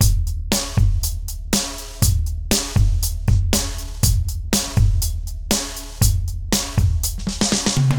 Duża dynamika, sami sprawdźcie.